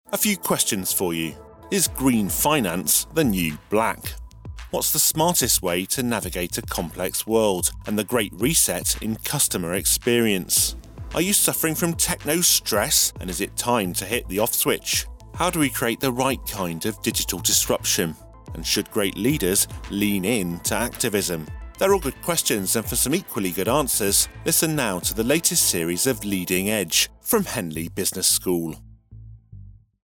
We sponsored a package of presenter led audio teasers across both Acast and Spotify News & Politics podcasts.